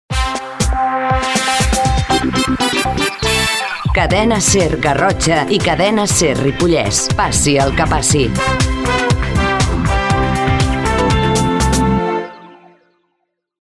b9bec5d7f81ac3fc9443c12a6591bf70ca03debd.mp3 Títol Cadena SER Garrotxa Emissora Cadena SER Garrotxa Cadena SER Titularitat Privada estatal Descripció Identificació de Cadena SER Garrotxa i Cadena SER Ripollès.